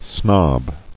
[snob]